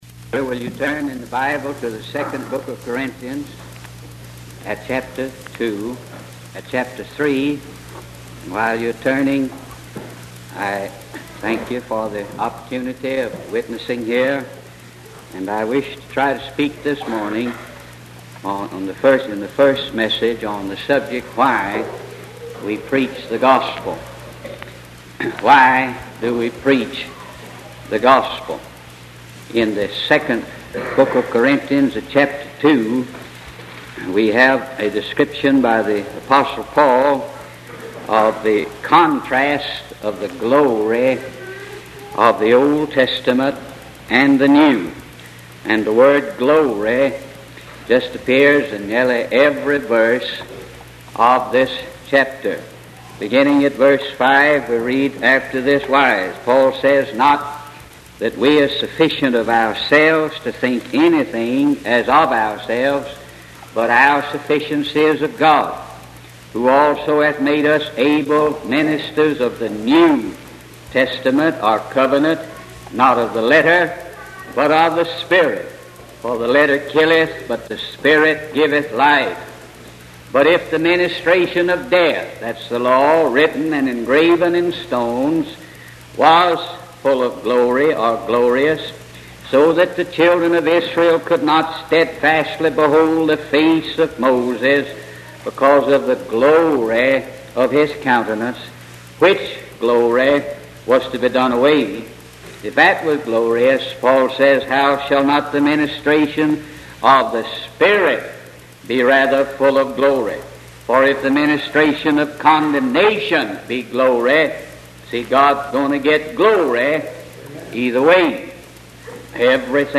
In this sermon, the preacher emphasizes the importance of knowing and experiencing the glory of God through Jesus Christ. He encourages the listeners to truly understand and believe in the gospel, which is the good news about Jesus and his life, death, resurrection, and rule.